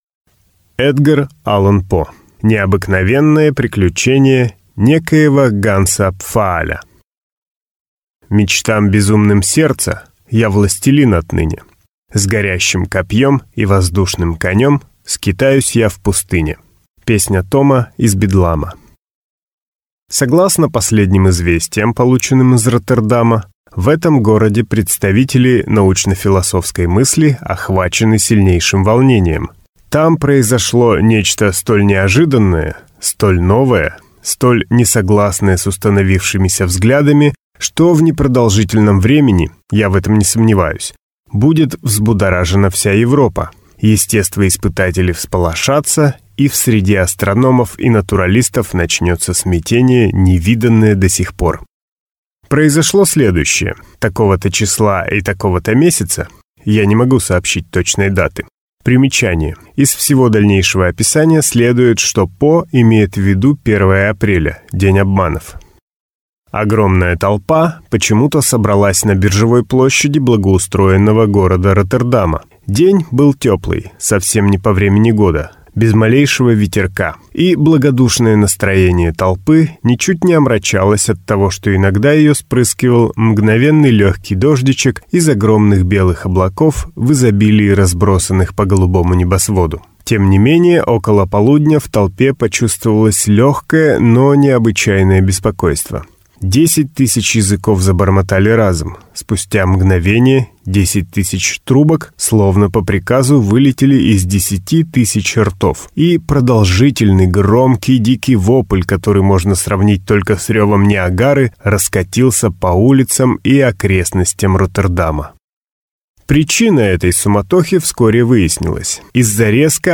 Аудиокнига Необыкновенное приключение некоего Ганса Пфааля | Библиотека аудиокниг